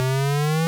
player_sending_blocks.wav